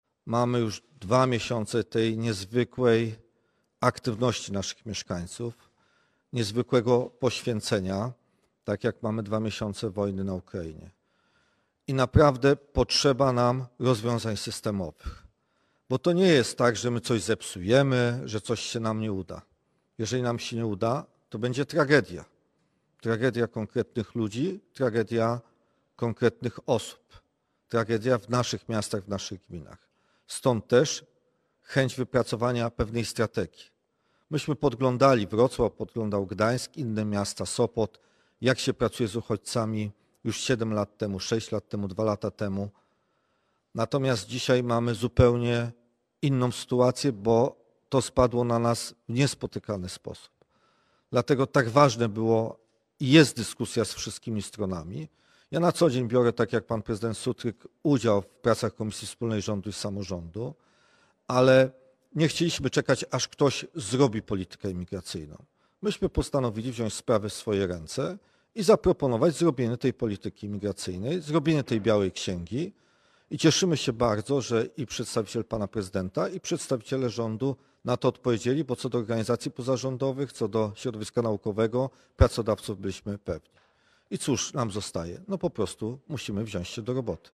– Widzimy olbrzymią solidarność naszych mieszkańców i ją podziwiamy –  podsumował Jacek Karnowski – prezydent Sopotu.
Obradom Samorządowego Okrągłego Stołu przysłuchiwało się kilkaset osób, które zasiadły na widowni we wrocławskiej Hali Stulecia.